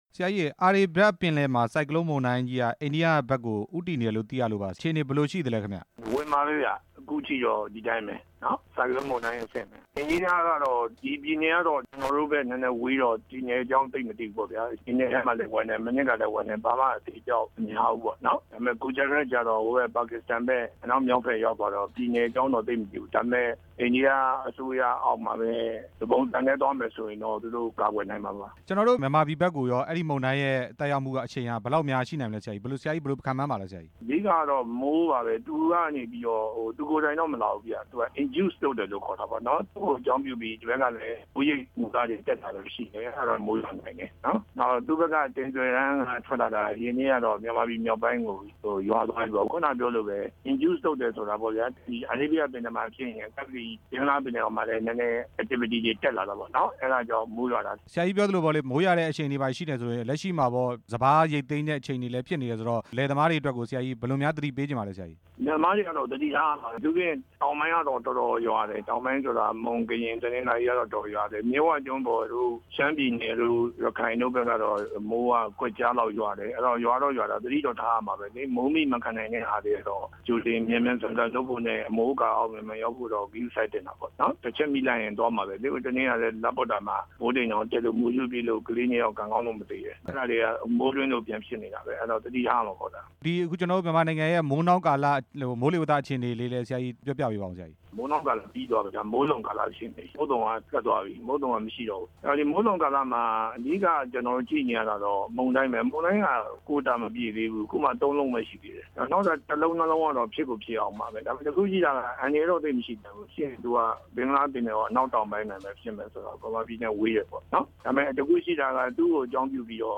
ဒေါက်တာ ဦးထွန်းလွင်ကို မေးမြန်းချက်